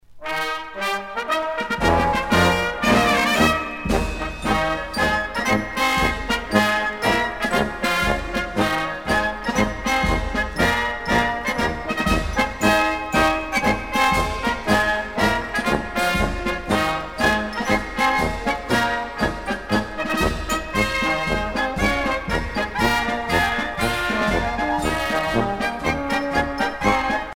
Fonction d'après l'analyste gestuel : à marcher